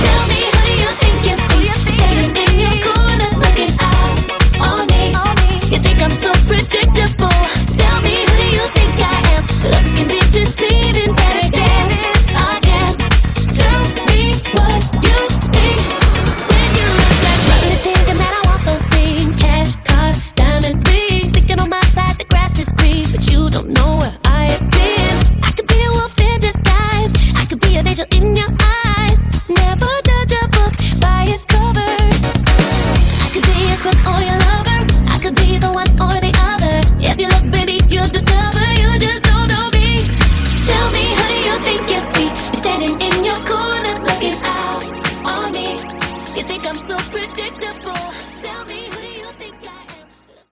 Dj Intro Outro – Get You